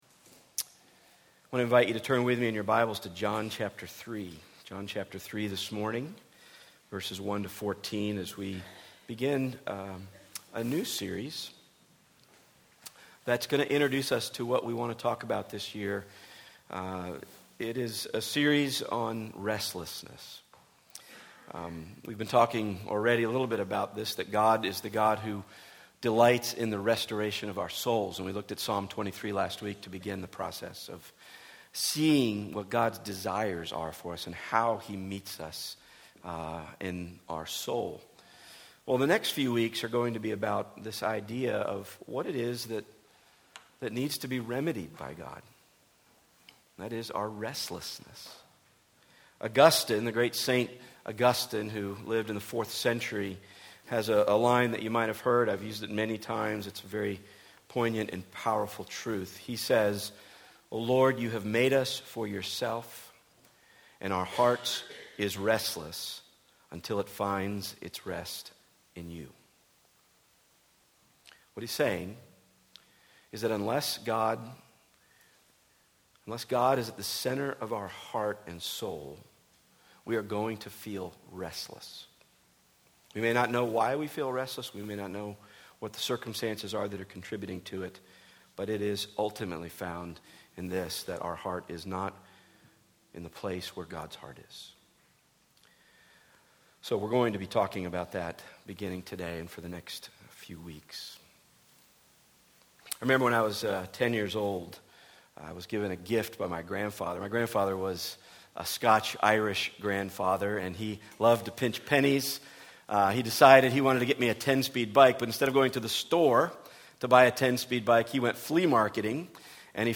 Service Type: Weekly Sunday